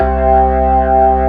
55O-ORG05-C2.wav